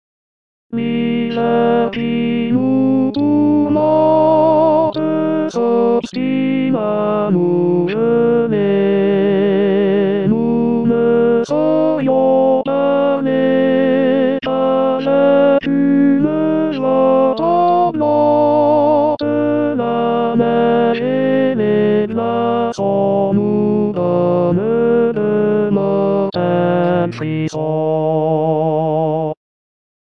L'interprétation demande un soutien abdominal sans faille, car on doit accentuer une note sur deux.
L'enregistrement est fautif, mais pas la partition qui a été corrigée.
Lully-02-Tenor-1.mp3